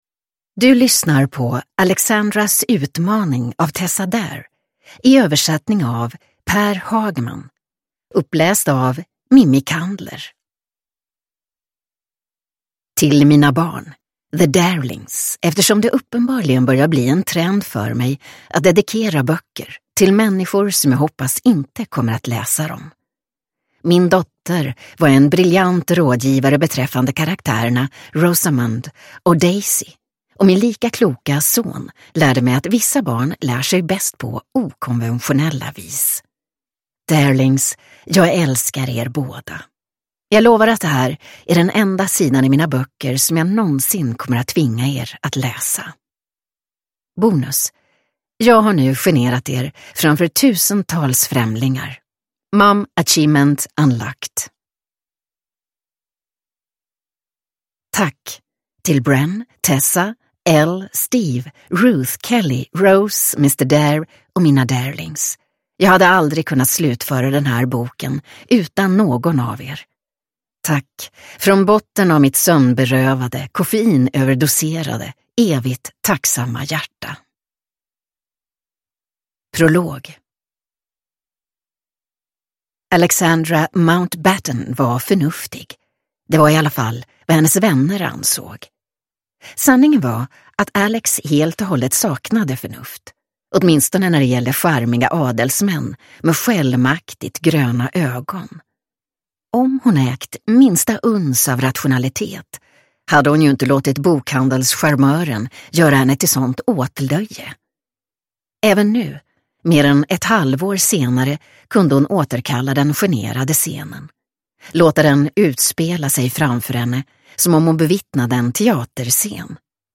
Alexandras utmaning – Ljudbok – Laddas ner